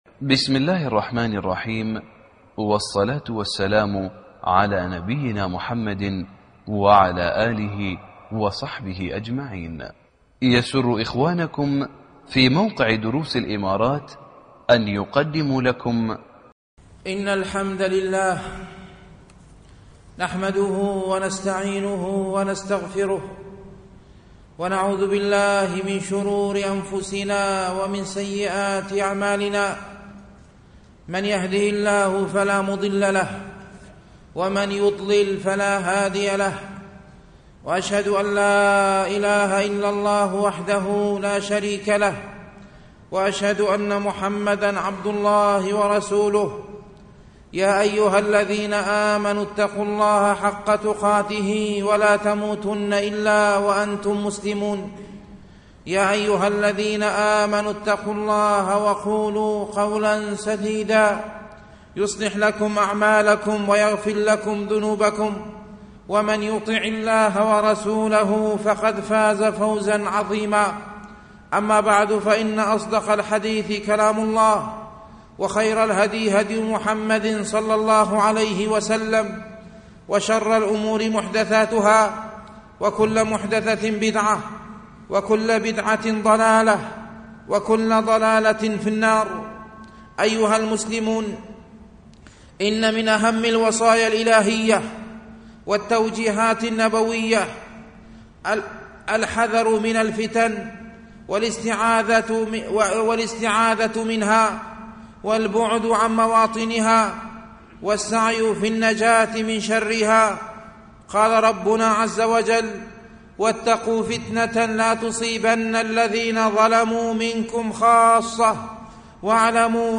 31 ديسمبر 1899 م 11.4M 00:19:48 مواعظ ورقائق 190 196 باقي خطب الشيخ كل الخطب سماع الخطبة تحميل الخطبة شارك